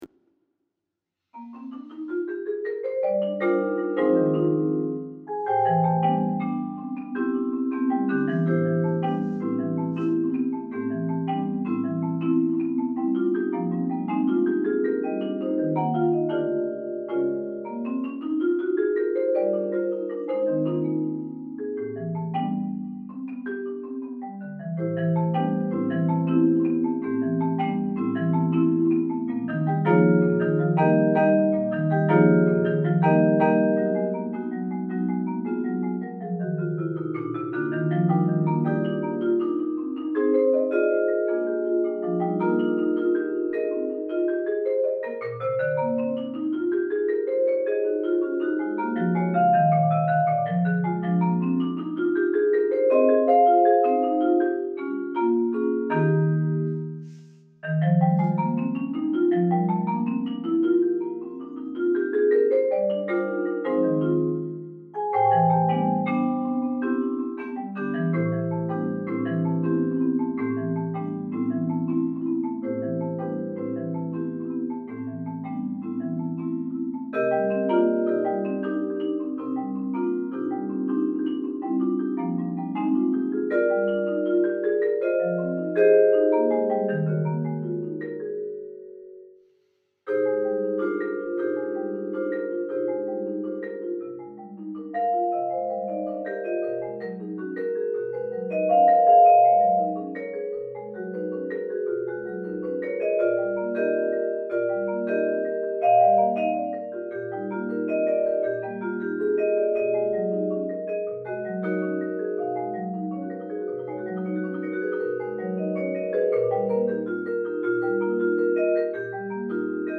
duet for marimba and vibraphone